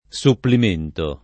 supplimento [ S upplim % nto ]